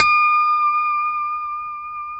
E6 PICKHRM1C.wav